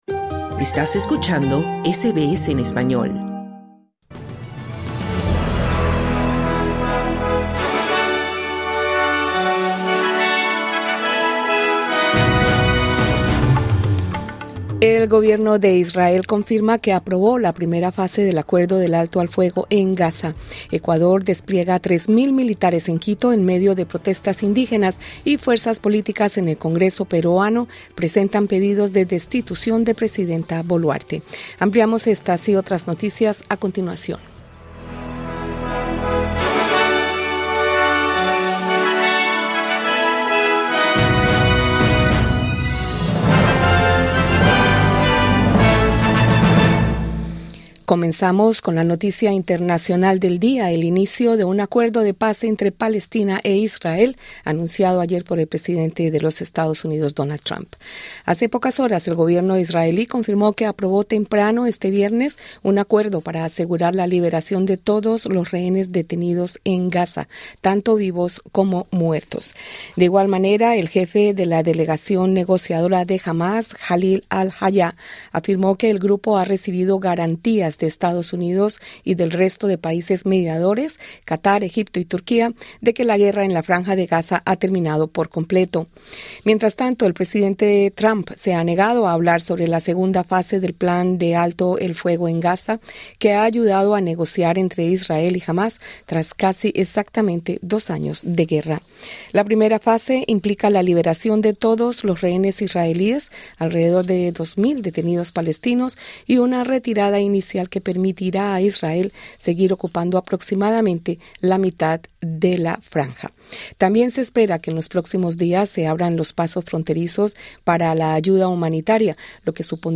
Noticias SBS Spanish | 10 octubre 2025